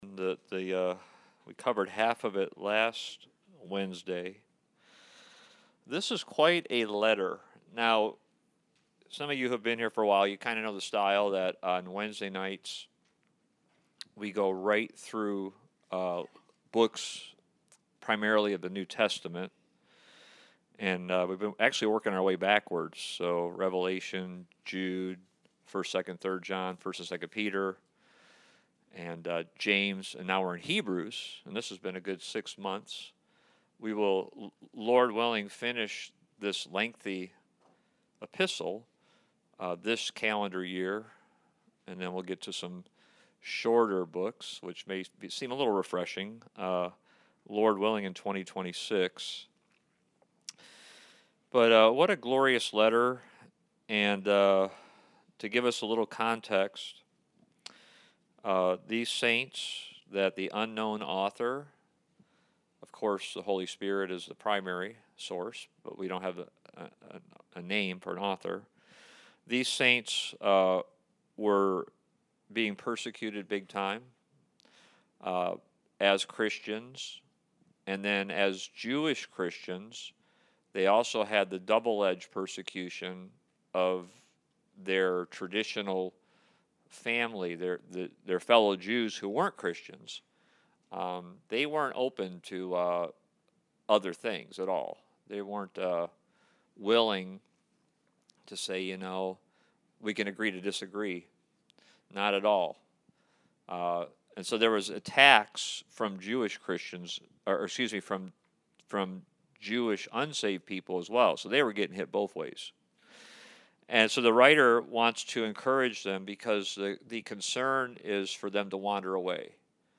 Series: Study of Hebrews Service Type: Wednesday Night